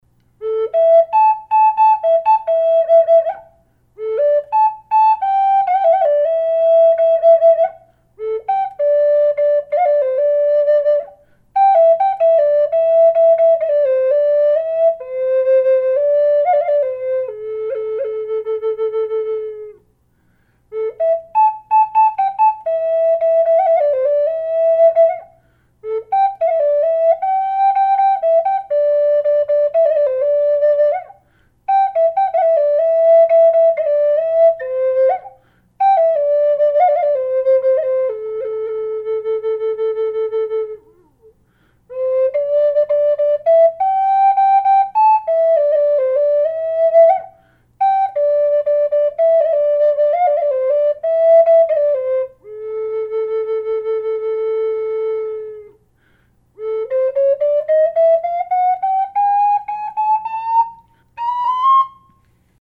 A minor old growth Western Red Cedar Flute.
Gorgeous voice & tone.
Sound sample with no effect
A-cedar-oldgrowth-01.mp3